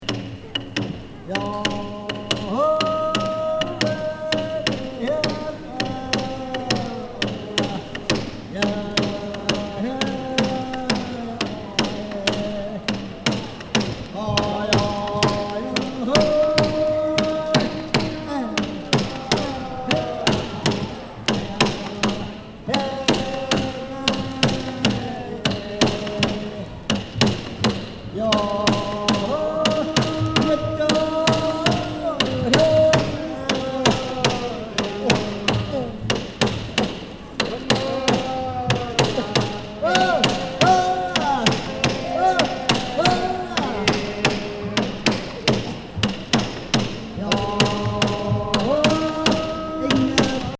イヌイットの太鼓を使った遊び？踊り？の音楽。掛け声も言葉の意味はよくわからず、独特の調子が面白いです。